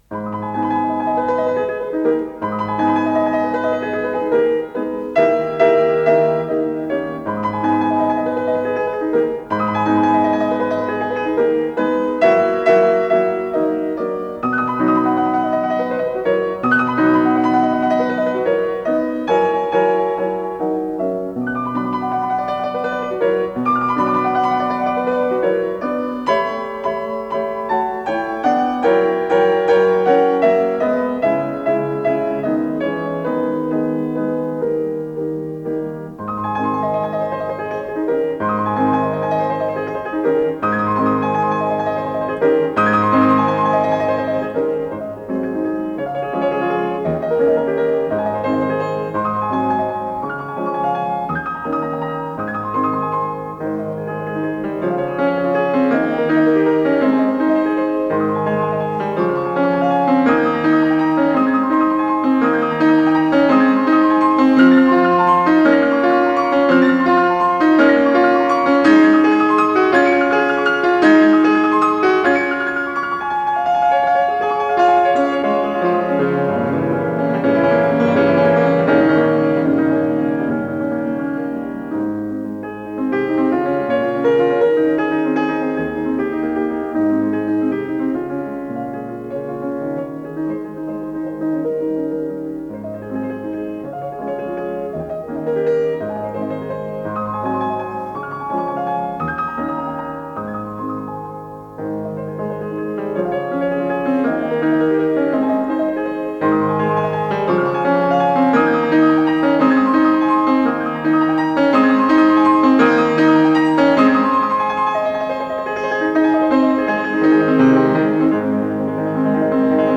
с профессиональной магнитной ленты
ПодзаголовокСоч. 90, ля бемоль мажор
ИсполнителиОлег Бошнякович - фортепиано
ВариантДубль моно